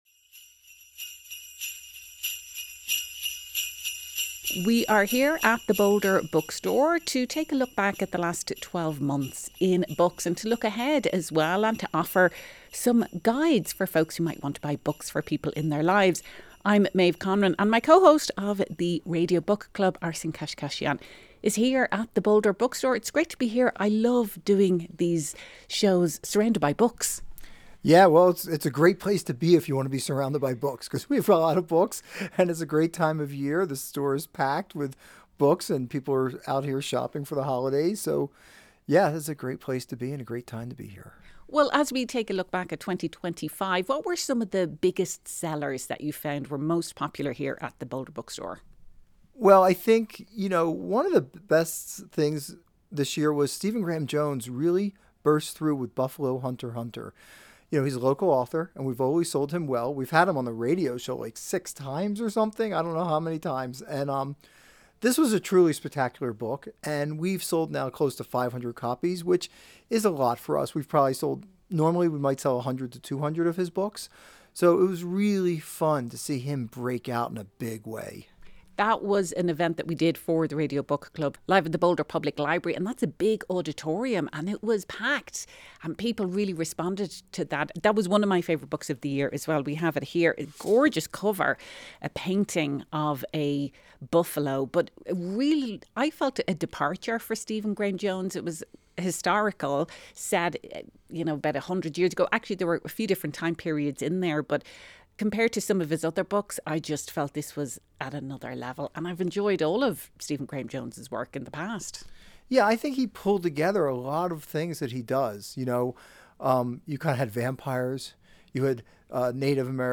As 2025 draws to a close, we check in with book sellers at the Boulder Bookstore to find out what books they enjoyed reading this year, and what recommendations they have for readers of all ages.